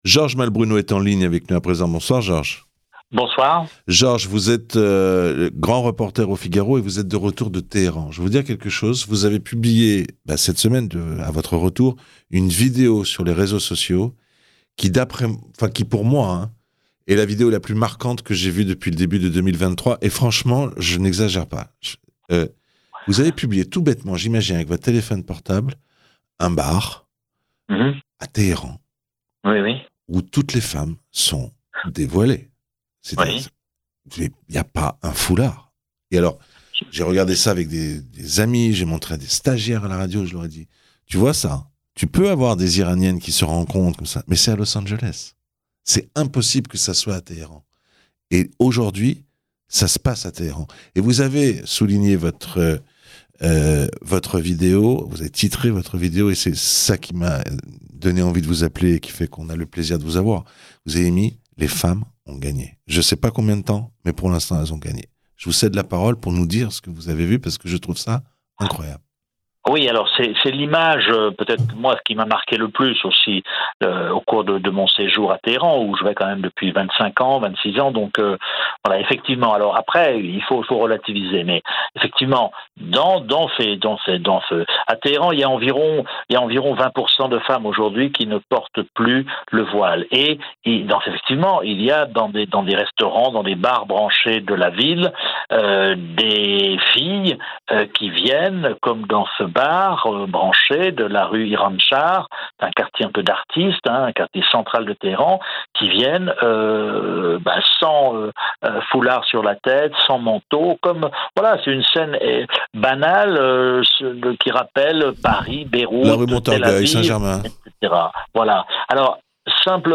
Les autorités islamiques ne peuvent plus arrêter les mouvement. Il est l'invité de Radio shalom